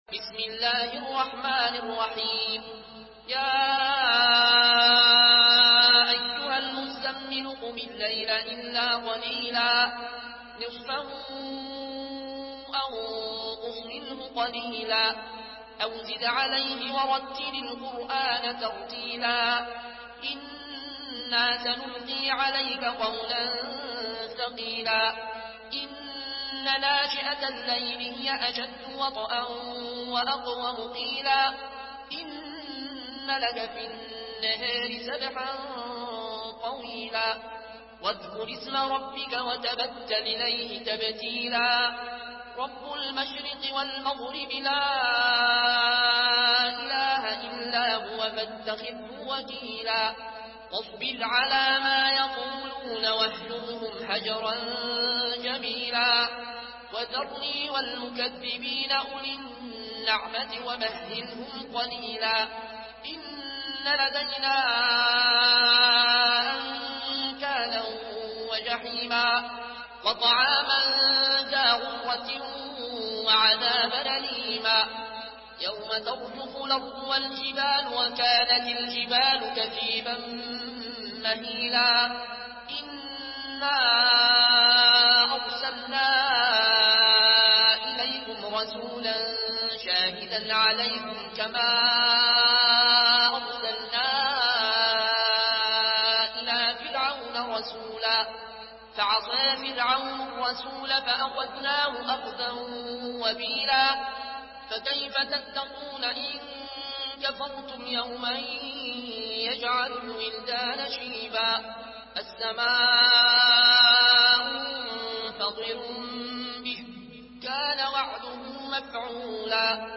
Surah Al-Muzzammil MP3 in the Voice of Al Ayoune Al Koshi in Warsh Narration
Murattal